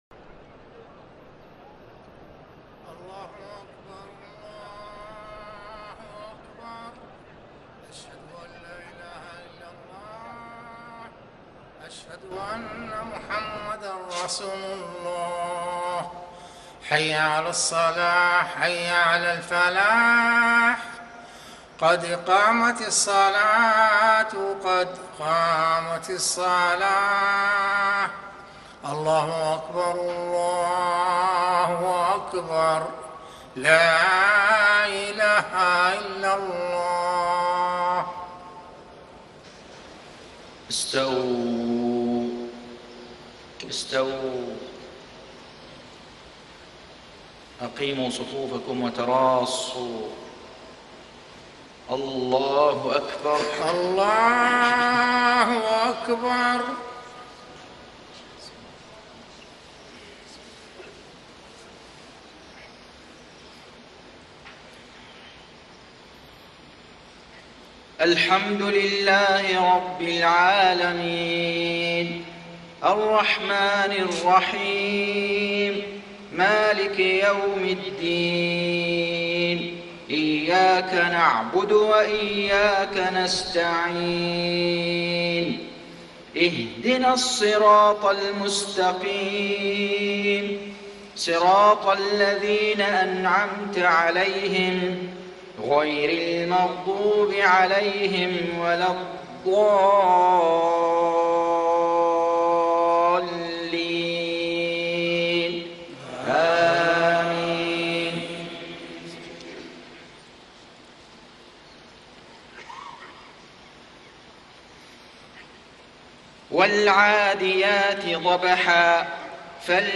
صلاة المغرب 7-2-1435 سورتي العاديات و القارعة > 1435 🕋 > الفروض - تلاوات الحرمين